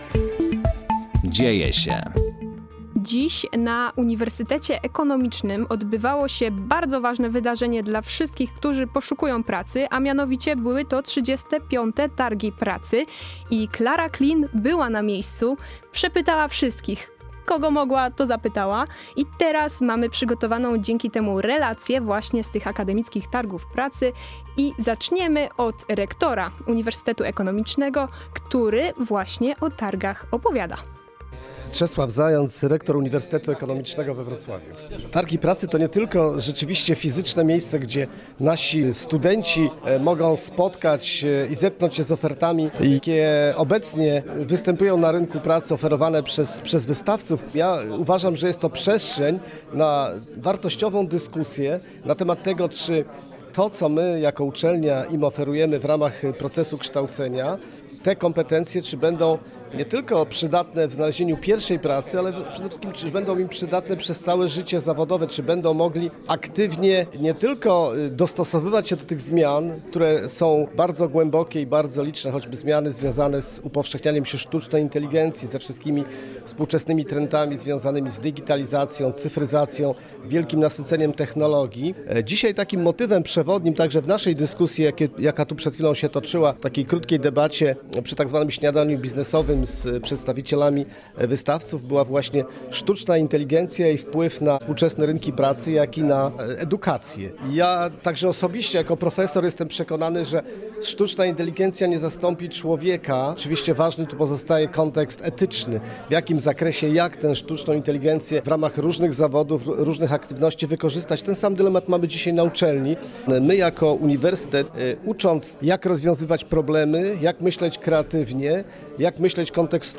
O tym, co działo się na Targach Pracy, mówiliśmy w popołudniowej audycji „Dzieje się”: